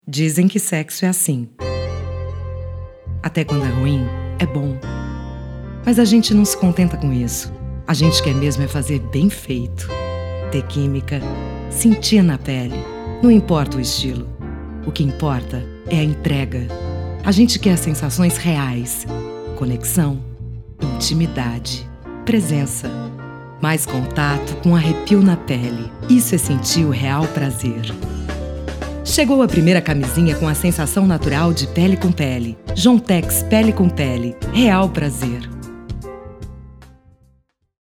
Feminino
Voz Padrão - Grave 00:37